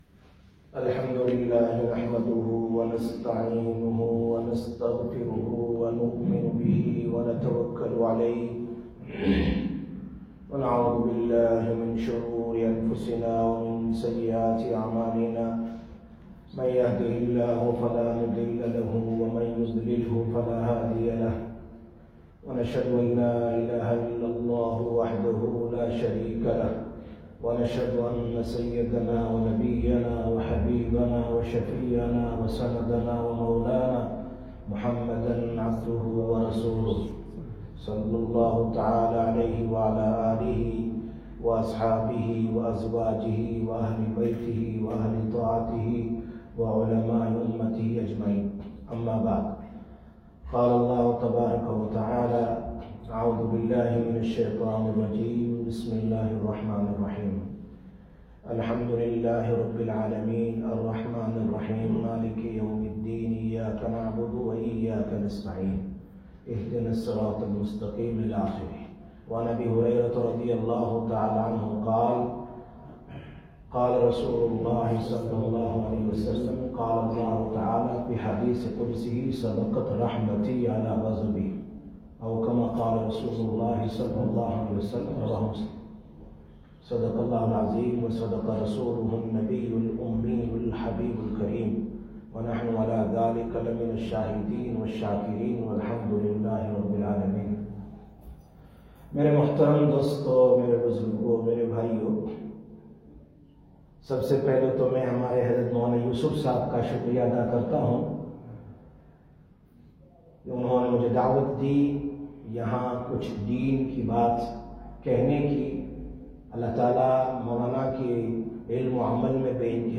09/09/2022 Jumma Bayan, Masjid-e-Noor Manchester